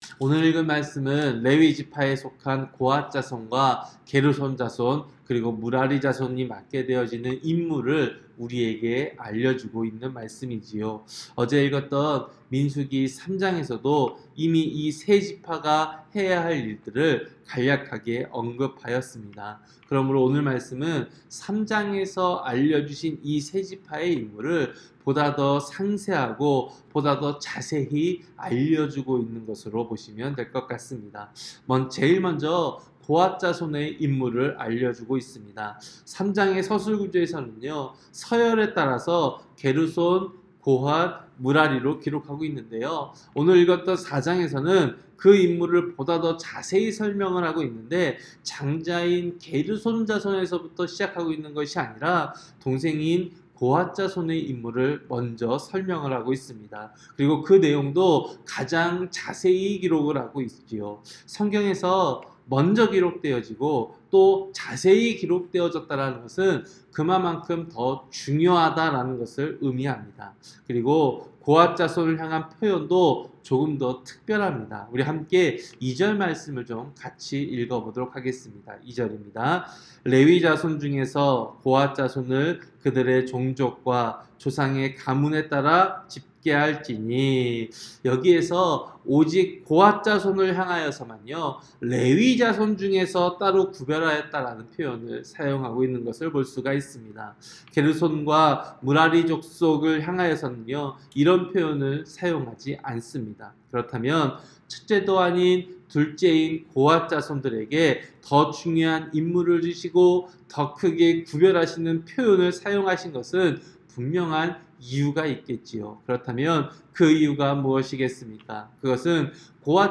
새벽설교-민수기 4장